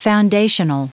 Prononciation du mot : foundational